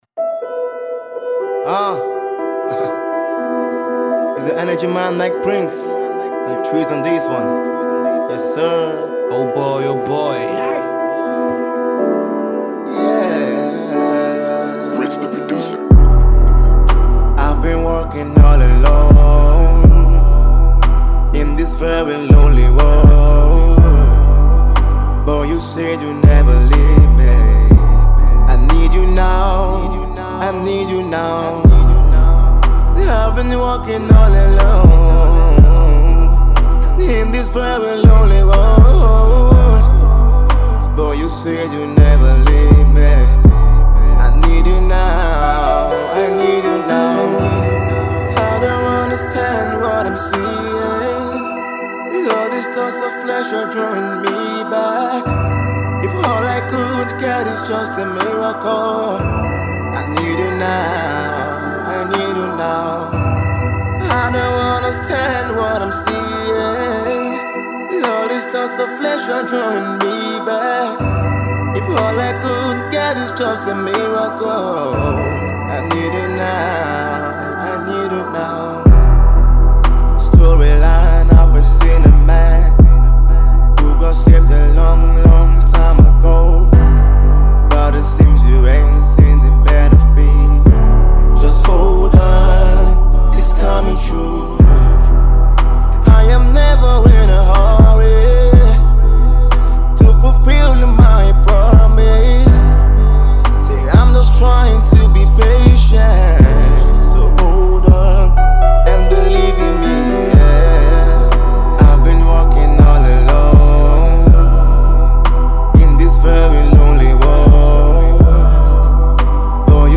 a smashing HipHop-Soul tune